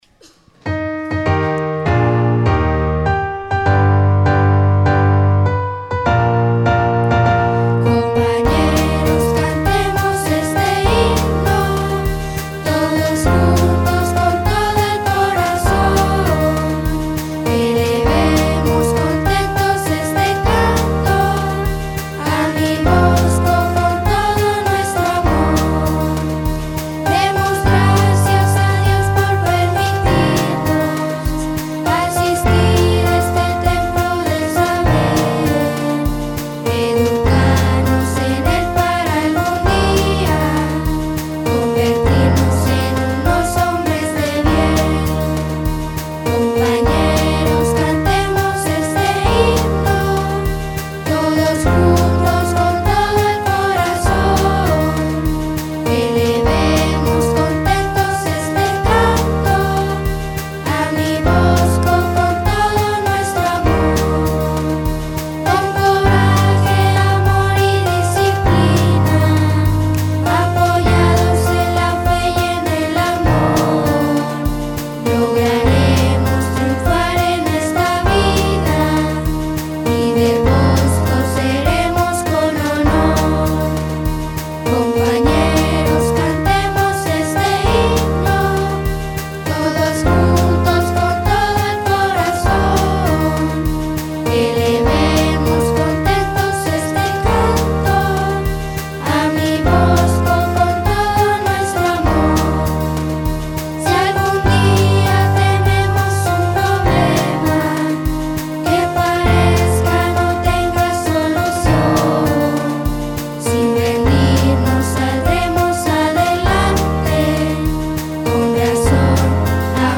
himno-bosco-cantado.mp3